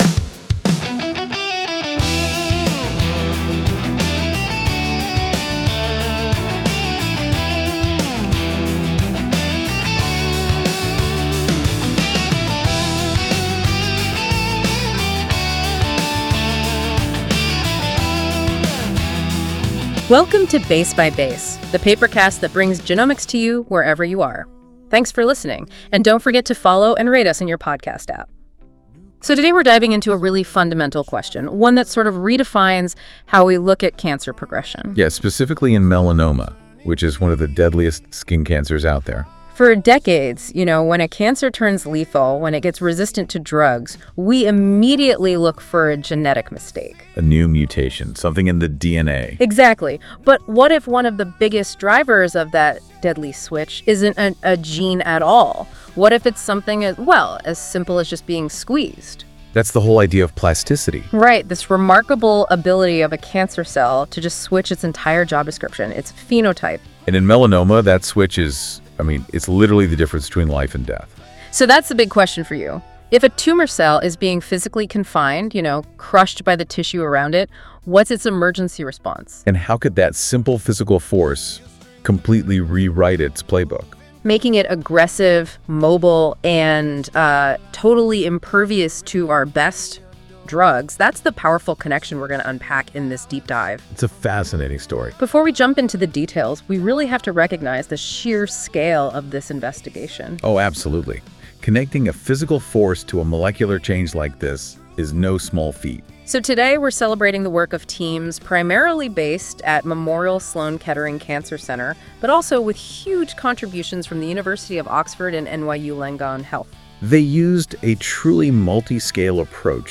Mechanical Confinement and the Shape-Shifting Life of Melanoma Cells Music:Enjoy the music based on this article at the end of the episode.